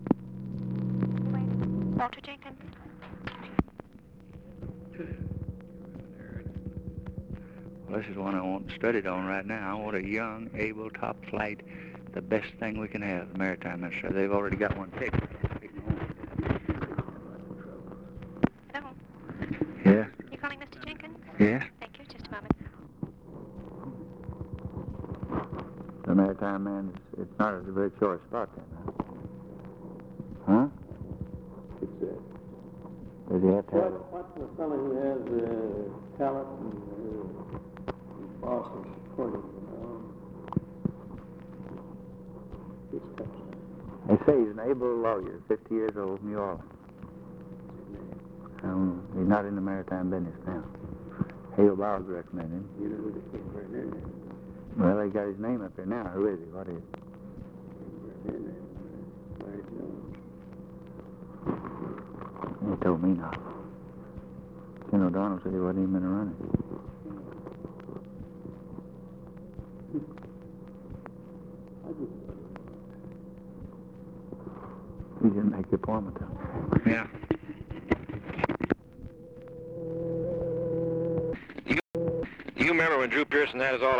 Conversation with OFFICE CONVERSATION
Secret White House Tapes